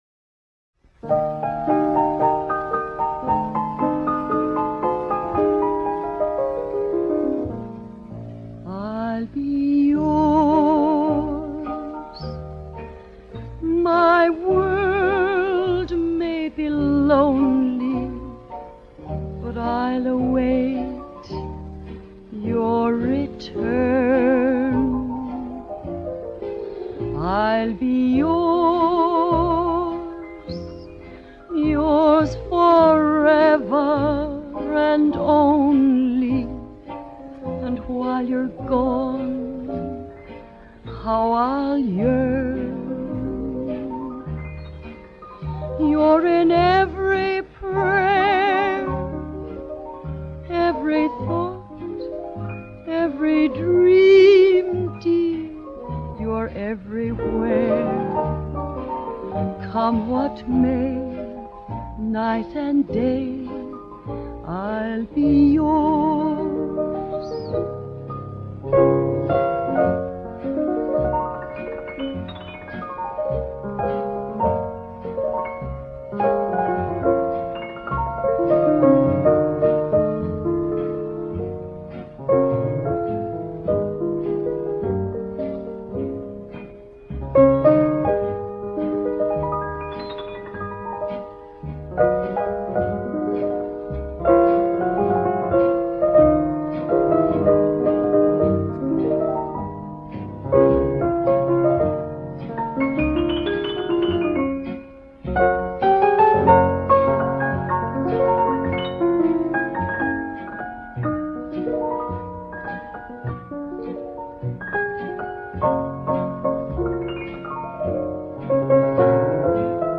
dall'affascinante voce